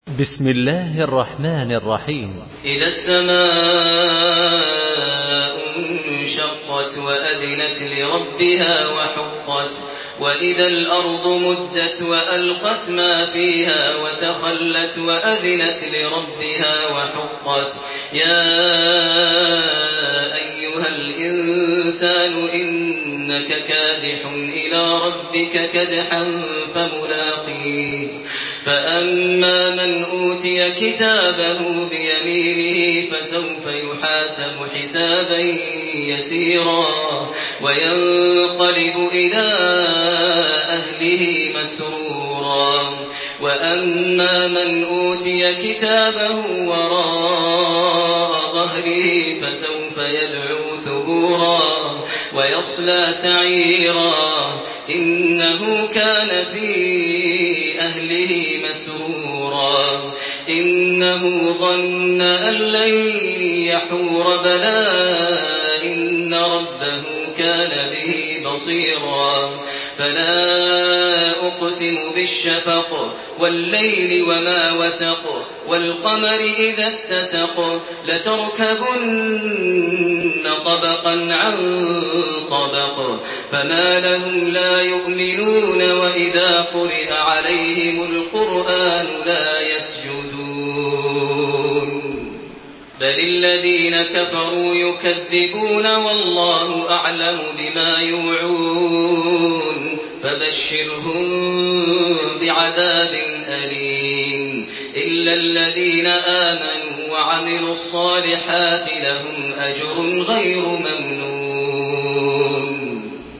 تراويح الحرم المكي 1428
مرتل